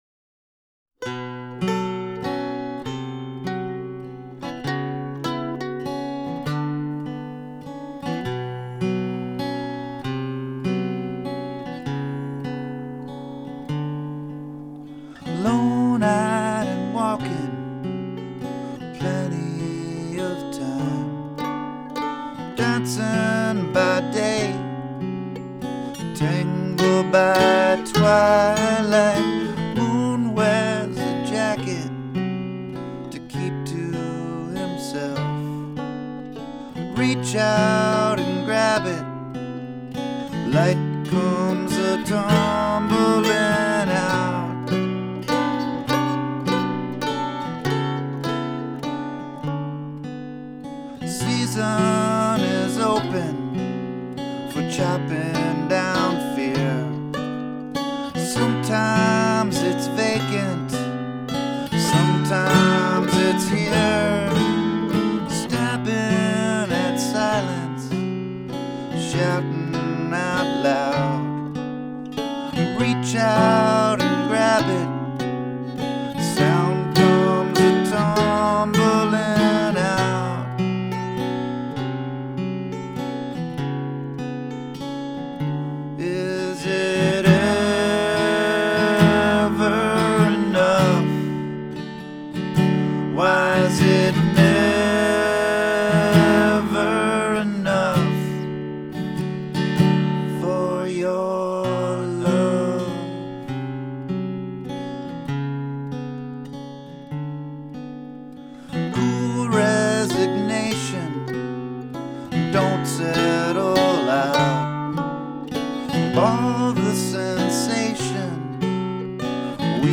Guitar and vocals
Mandolin, fiddle, and vocals